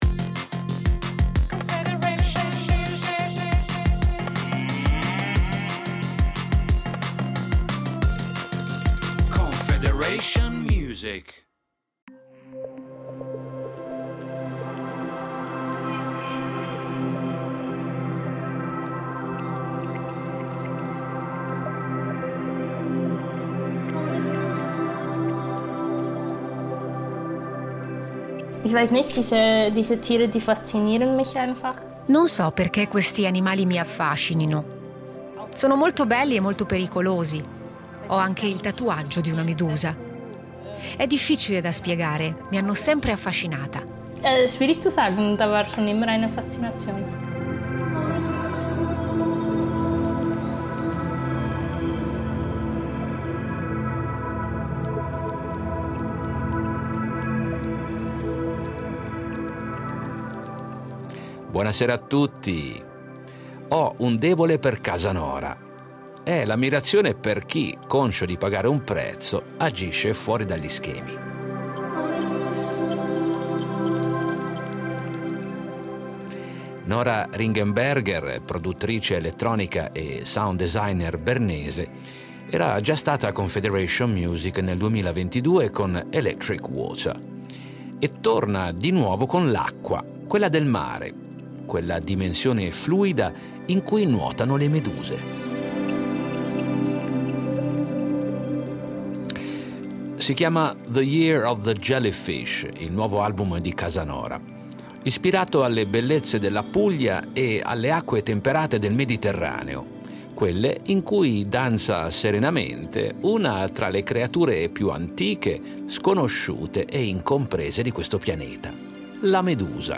Musica elettronica